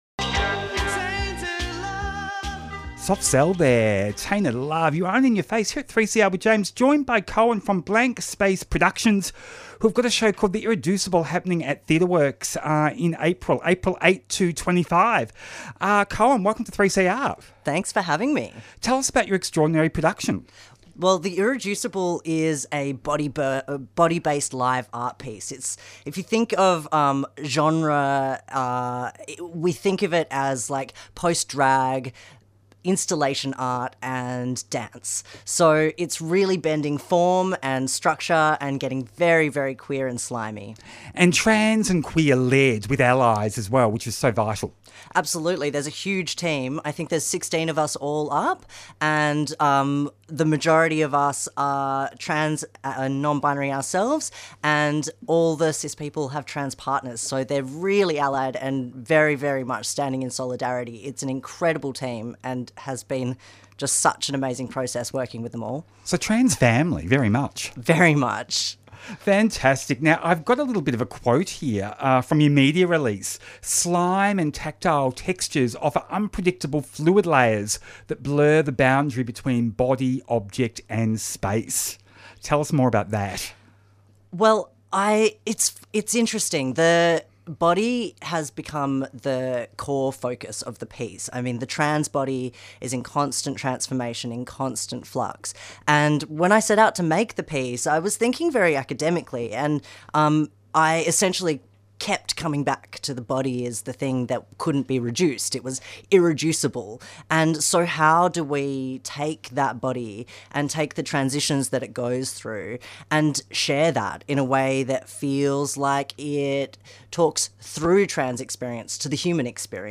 The Irreducible | Theatre Works Tweet In Ya Face Friday 4:00pm to 5:00pm Explores LGBTIQA+ issues with interviews, music and commentary.